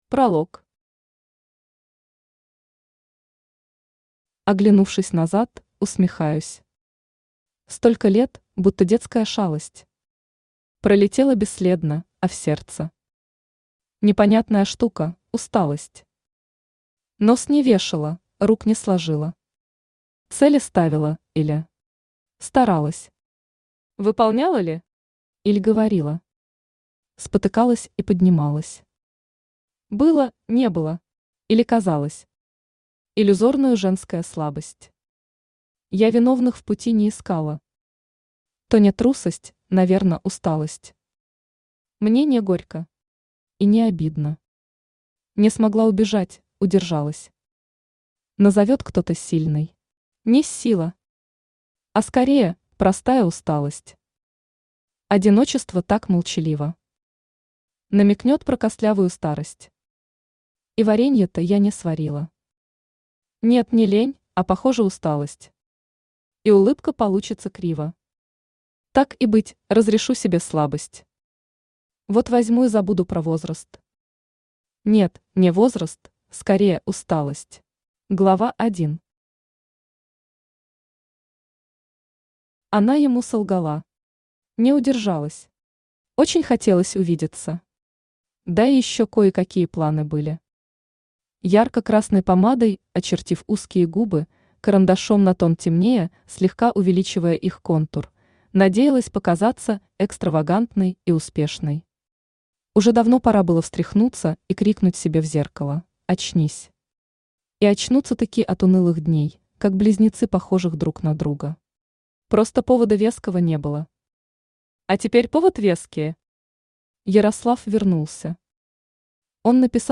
Aудиокнига Лживая бывшая Автор Ася Юрьевна Вакина Читает аудиокнигу Авточтец ЛитРес.